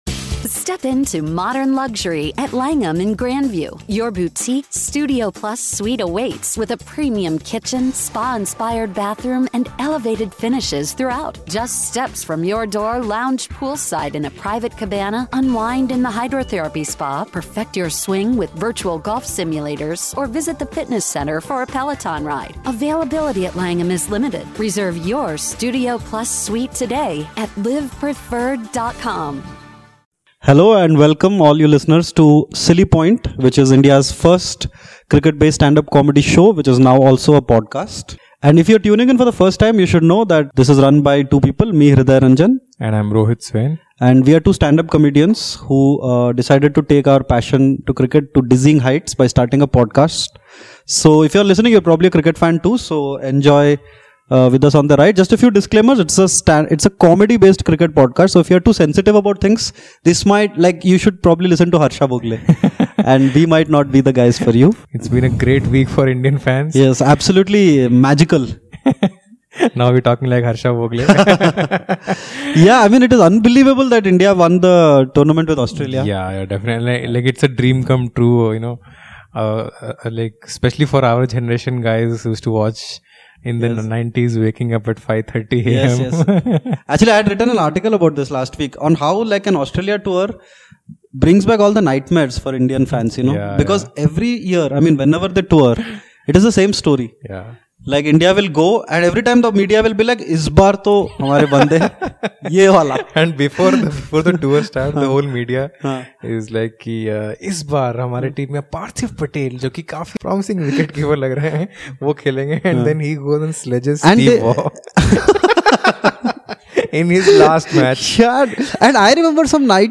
Presenting our cricket comedy podcast that's unlike any other.